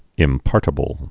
(ĭm-pärtə-bəl)